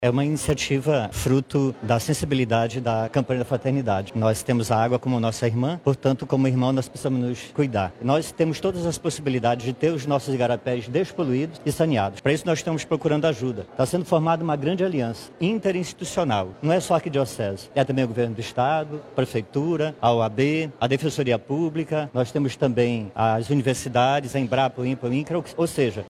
Em uma coletiva de imprensa realizada na manhã desta terça-feira, 26 de agosto, no auditório da Cúria Metropolitana, a Arquidiocese de Manaus apresentou a 31° edição do Grito dos Excluídos e Excluídas.